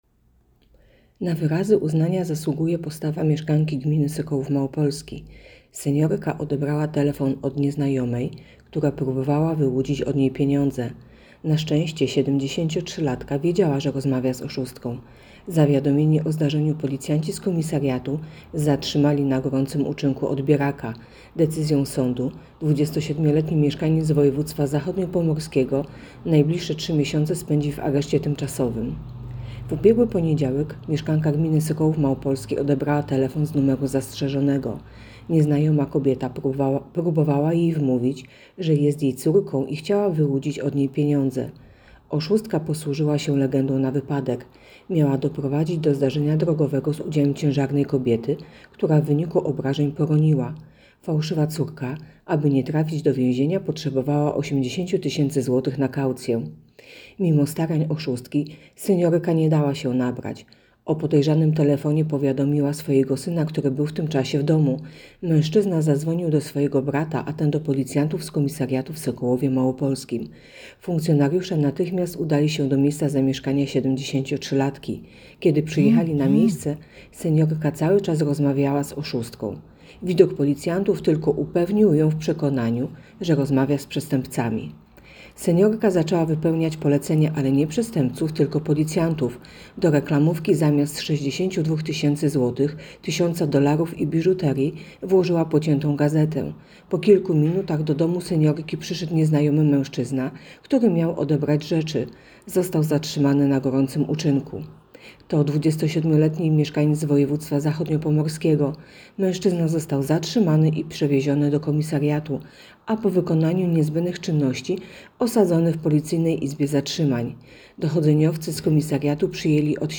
Opis nagrania: Nagranie informacji pt. Czujna seniorka nie dała się oszukać.